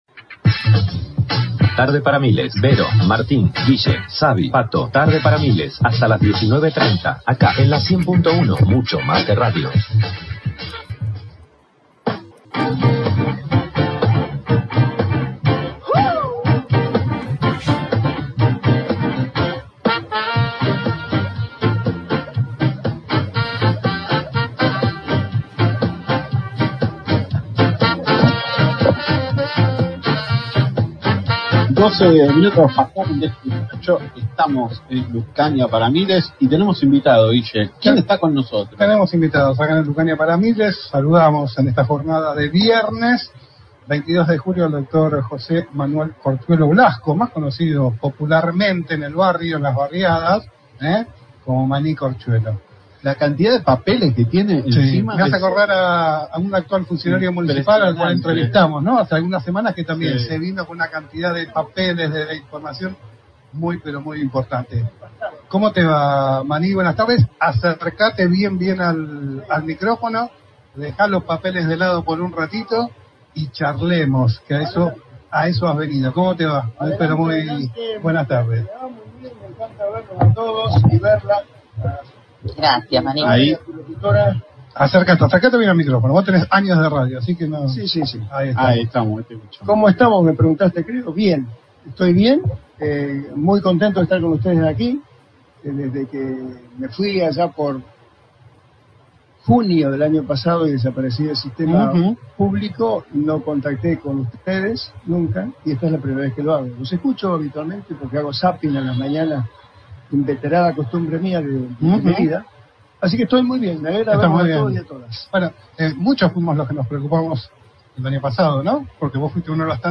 El exdiputado nacional y exministro de Salud de Chubut, José Manuel Corchuelo Blasco, dialogó con LaCienPuntoUno sobre la situación de la provincia en salud con respecto a las horas guardia y las renuncias múltiples en algunos sectores de distintos hospitales de Chubut.